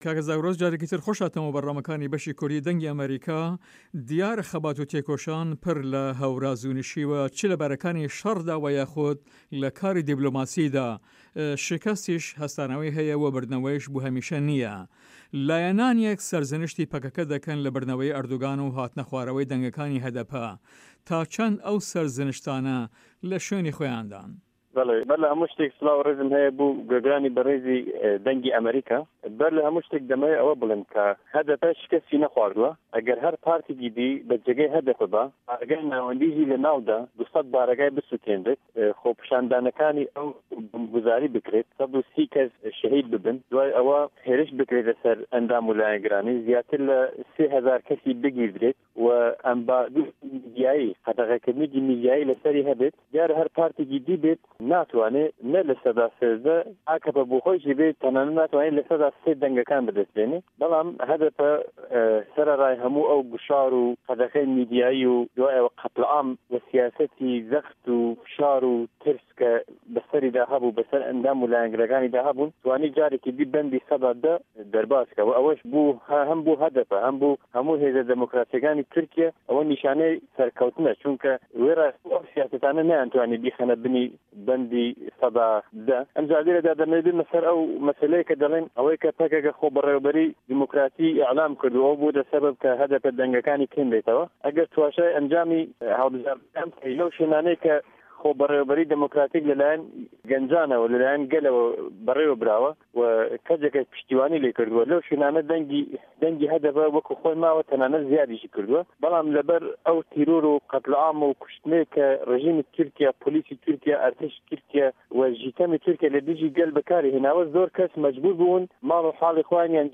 لەهەڤپەیڤینێکدا